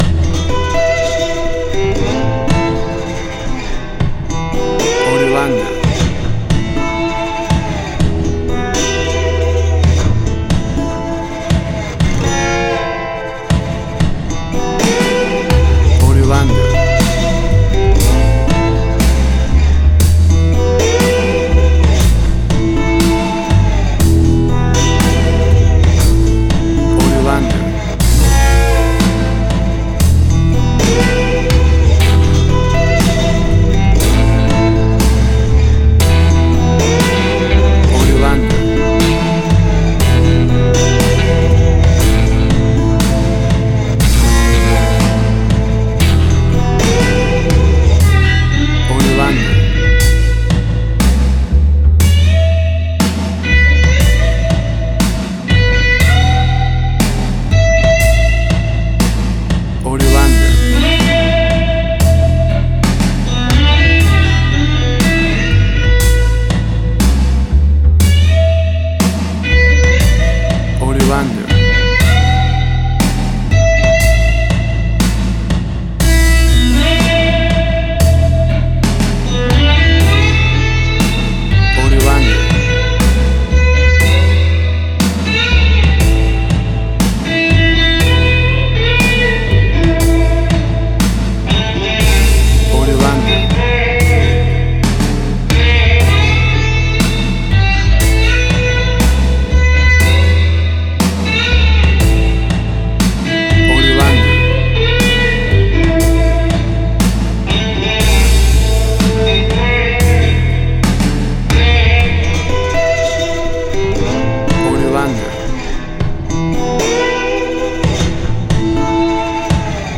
Old American Blues with a classic Vintage and raw vibe.
Tempo (BPM): 60